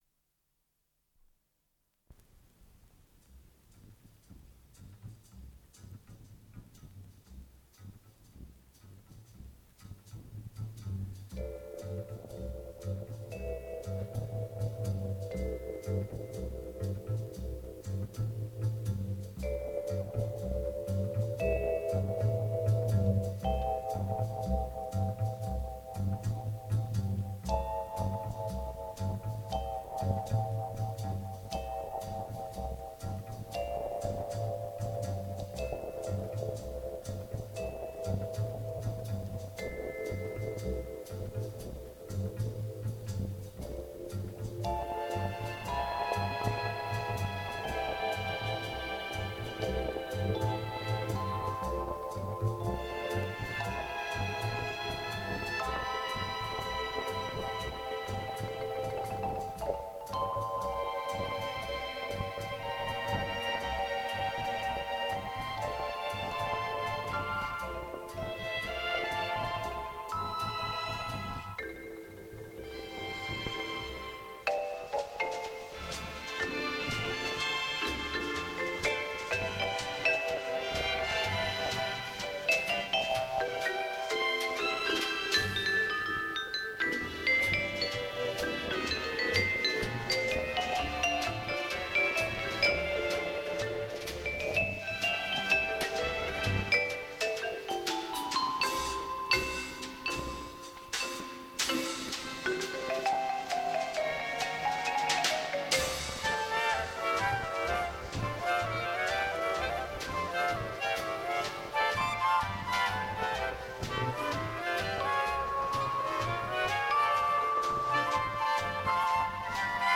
вибрафон
маримбафон
Дубльь моно